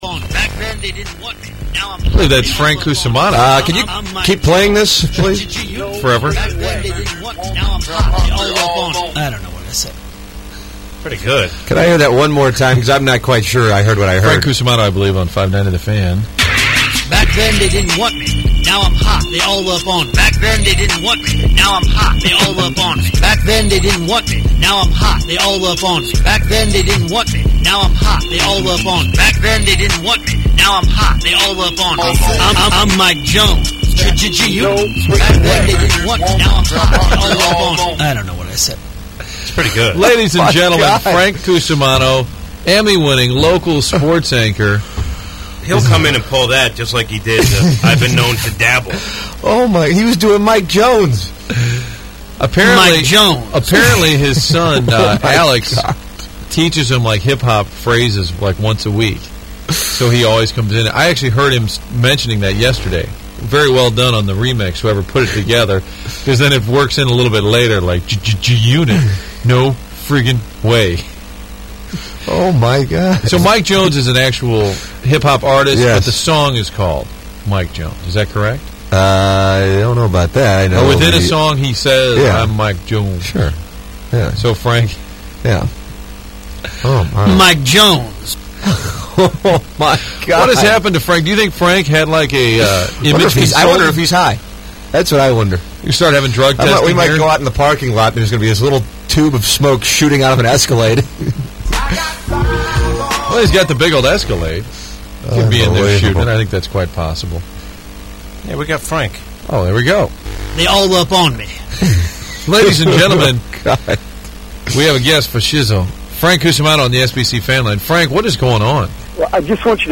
a pastor calls in to chat with the Morning Grind